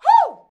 HUH 2.wav